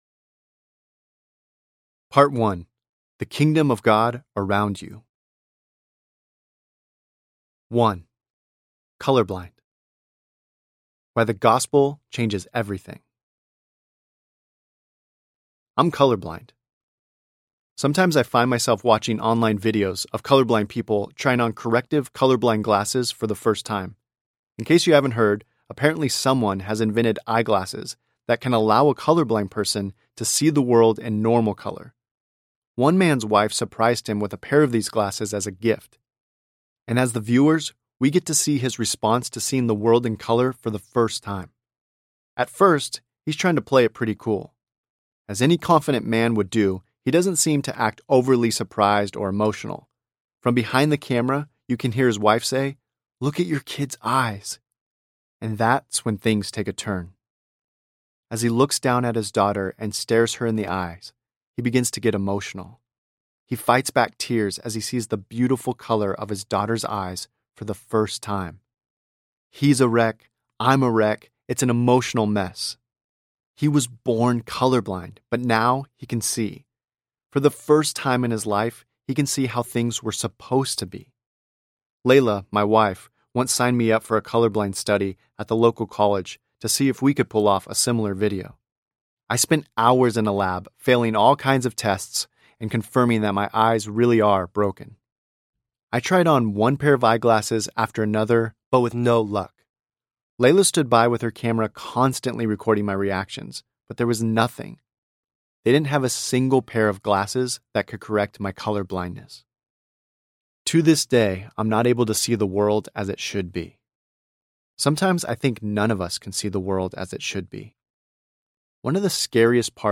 Dad Tired and Loving It Audiobook
4.0 Hrs. – Unabridged